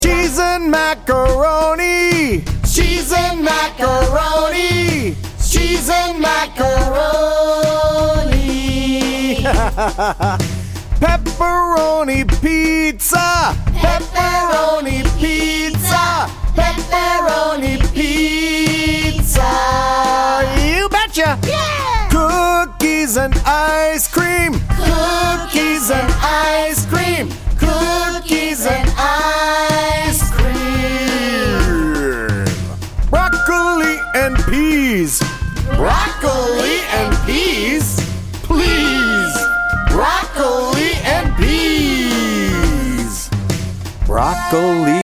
-Kids and adults singing together and taking verbal turns